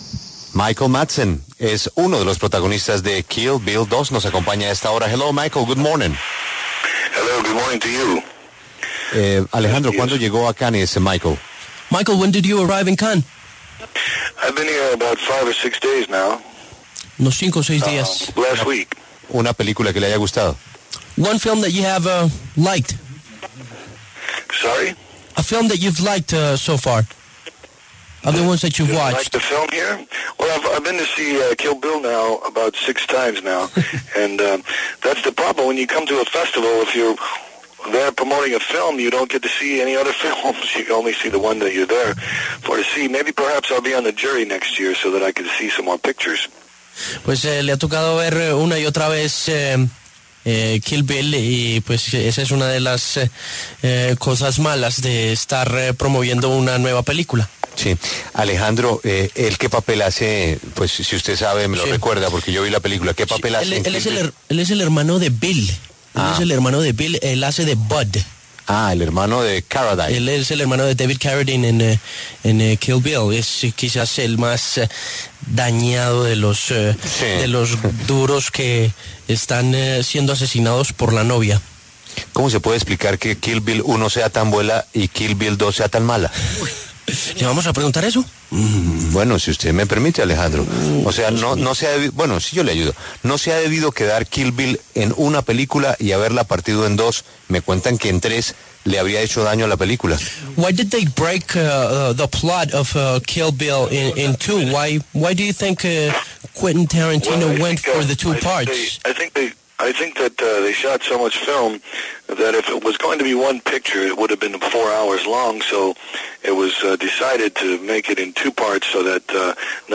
Entrevista con Kenneth Colley, actor británico de 'La guerra de las galaxias'